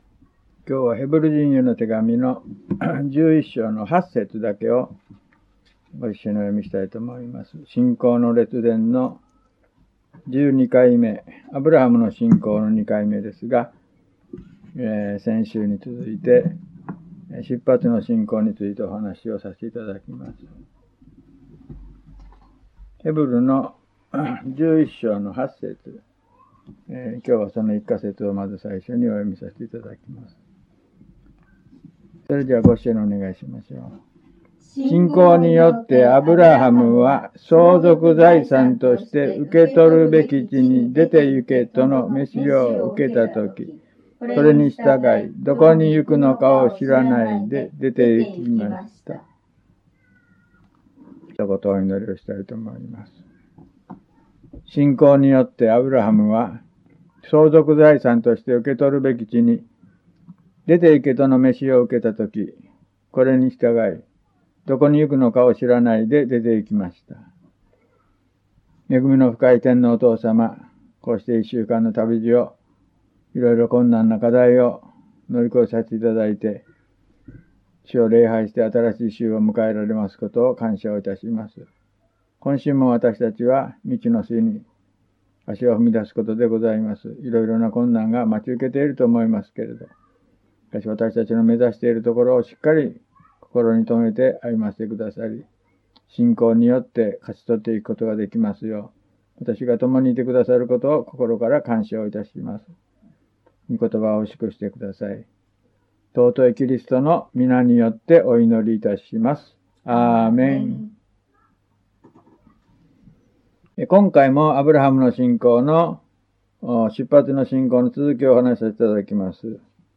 午前10時半 礼拝メッセージ